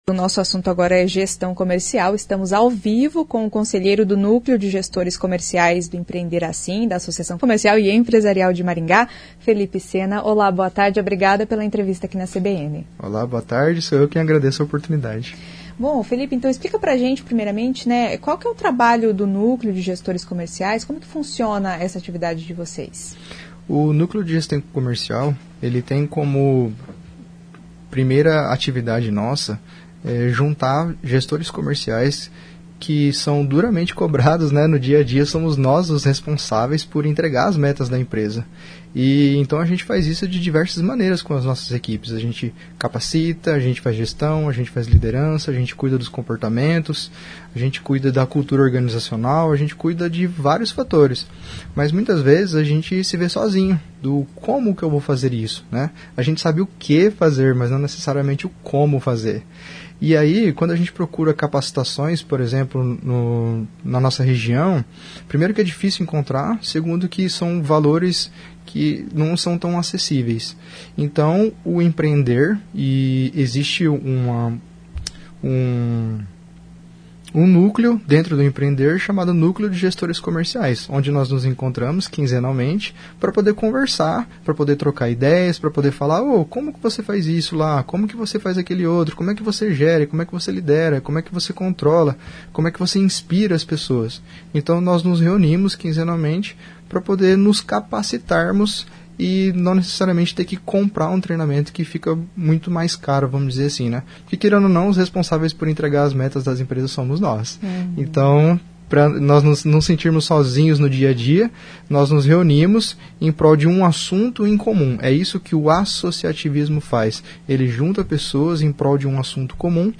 Nesta entrevista a discussão é sobre a Cultura organizacional e Gestão comercial.